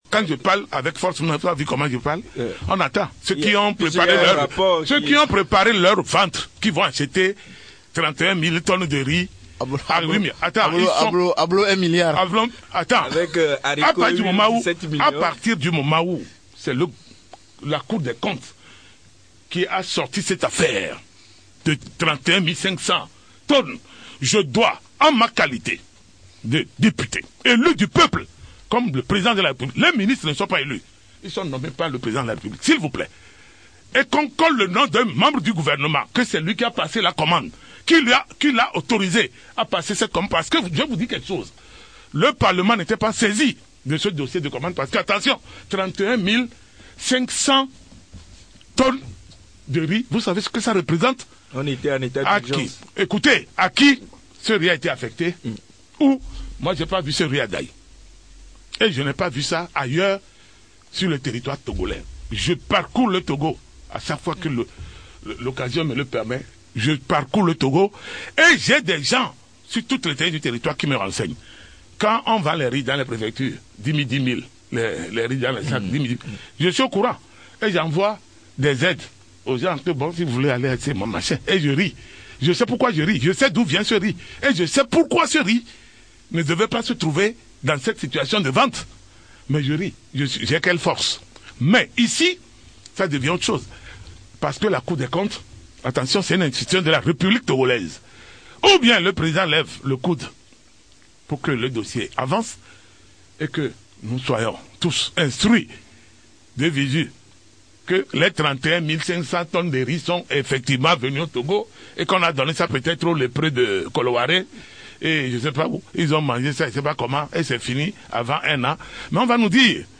Affaire de détournement de fonds Covid : Le député Abass Kaboua se prononce